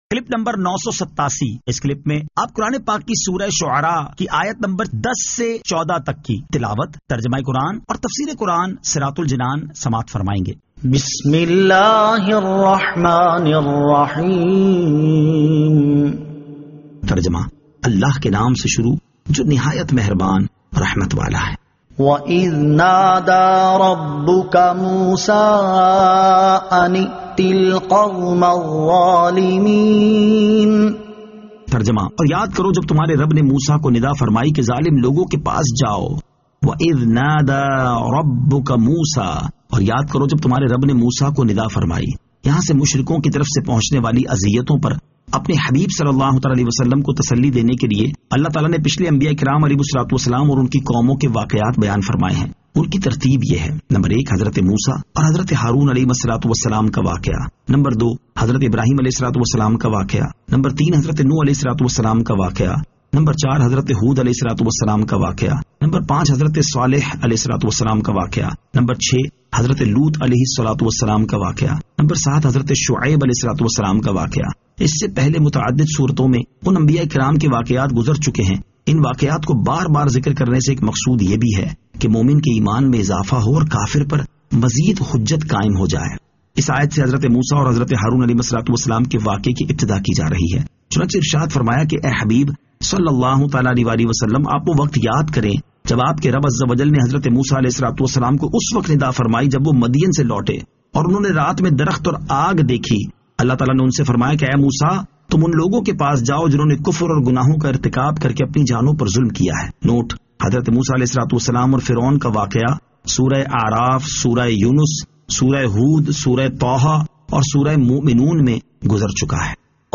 Surah Ash-Shu'ara 10 To 14 Tilawat , Tarjama , Tafseer